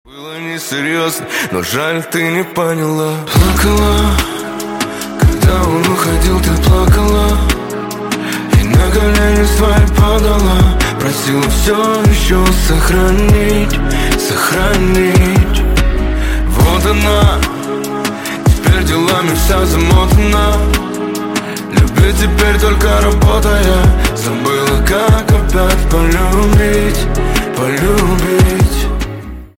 Грустные Рингтоны
Поп Рингтоны